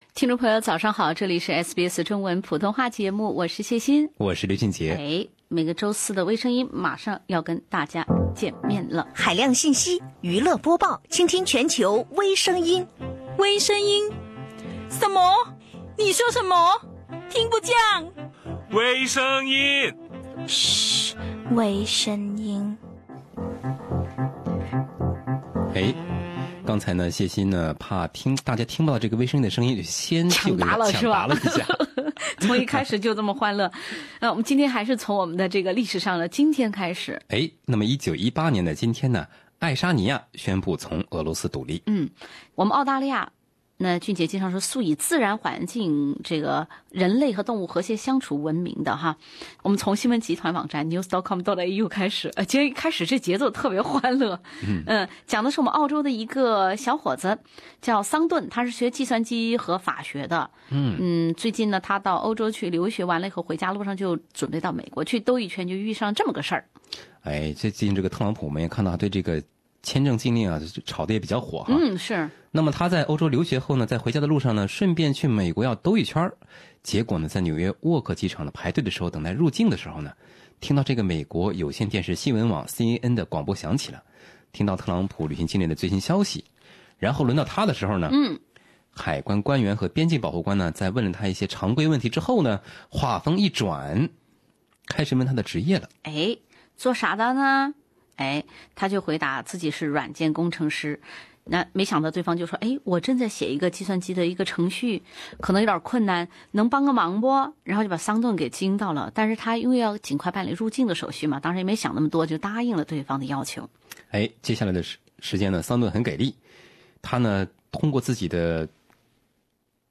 会写代码才过境，澳男效仿周幽王，一只鸡有十只爪，理财中心来搓麻。另类轻松的播报方式，深入浅出的辛辣点评；包罗万象的最新资讯；倾听全球微声音。